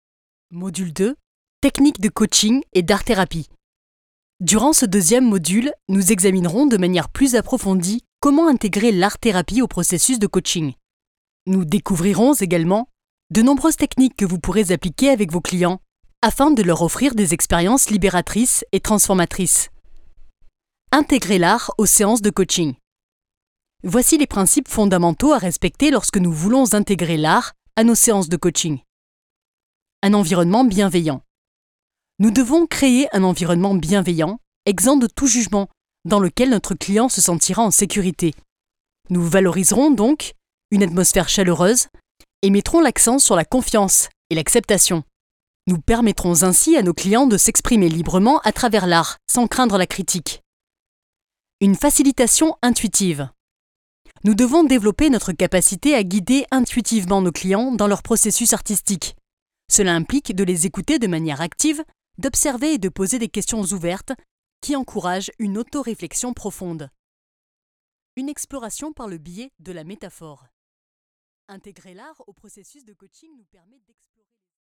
I’m a native French voice-over artist and producer with years of experience delivering high-quality voice work.
Gear: I use Ableton Live 11, a MOTU UltraLite interface, and a Blue Baby Bottle microphone for professional-grade sound.
Sprechprobe: eLearning (Muttersprache):